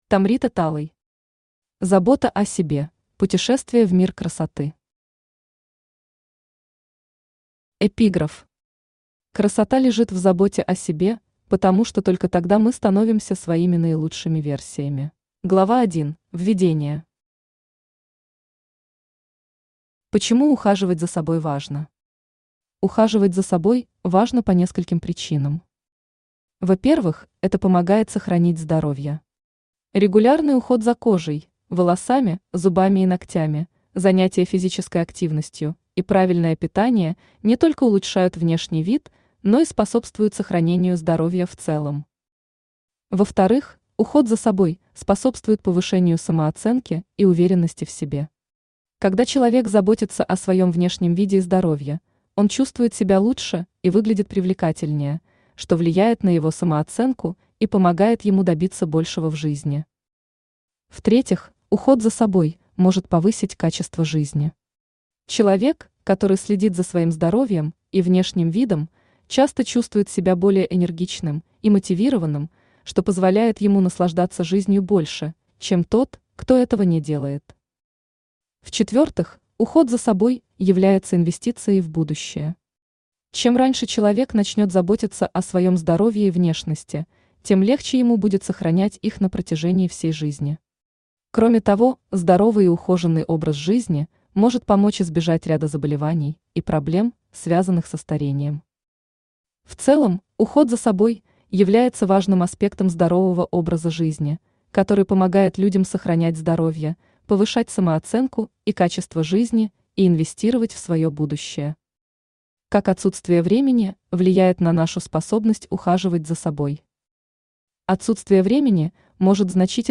Аудиокнига Забота о себе: Путешествие в мир красоты | Библиотека аудиокниг
Aудиокнига Забота о себе: Путешествие в мир красоты Автор Tomrita Talay Читает аудиокнигу Авточтец ЛитРес.